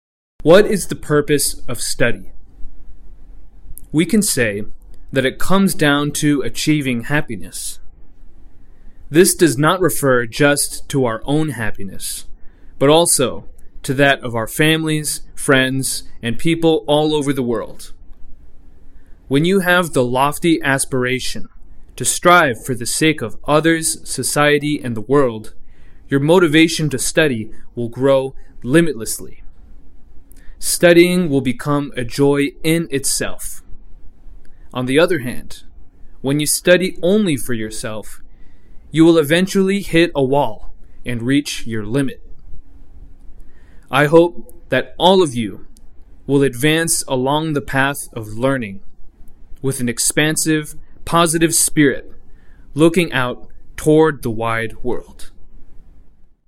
模範音声再生（通常版） 模範音声再生（通常版）